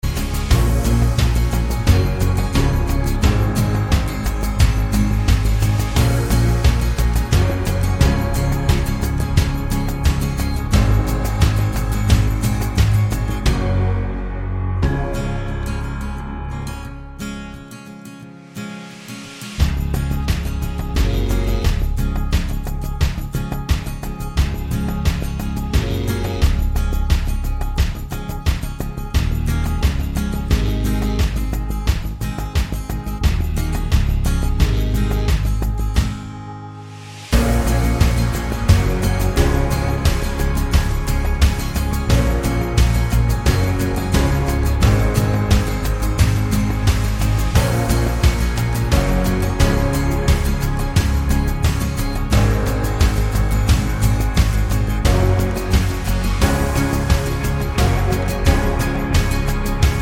no Backing Vocals Rock 2:54 Buy £1.50